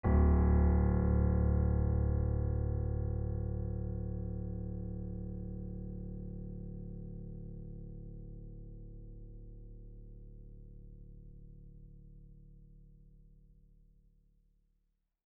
piano-sounds-dev
GreatAndSoftPiano